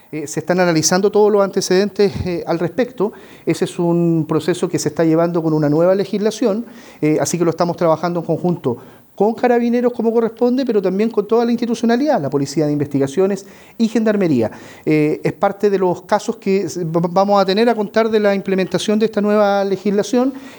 El delegado Presidencial del Bío Bío, Eduardo Pacheco, aseguró que este proceso se está realizando bajo la nueva normativa de funerales de alto riesgo que fue aprobada hace unas semanas en el Congreso y que entró en vigencia hace pocos días.